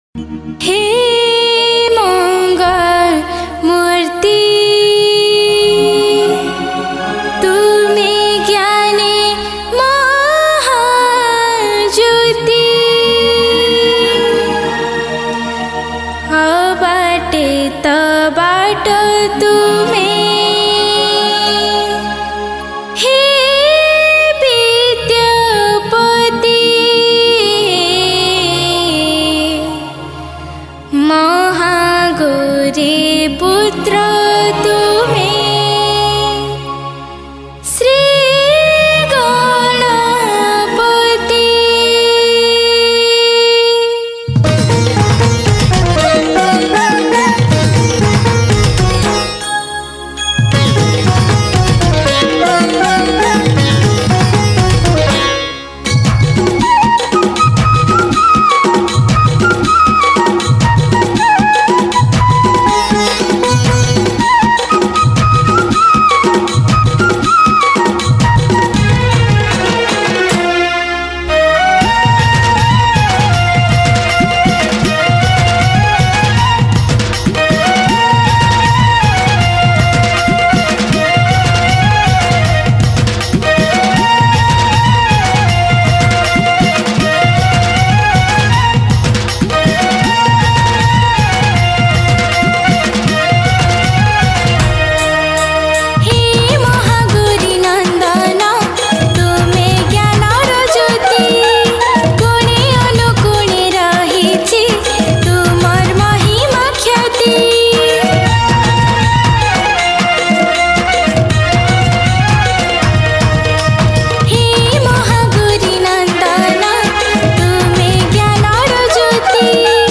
Category: Sambalpuri Bhajan Single Songs